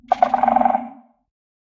tendril_clicks_4.ogg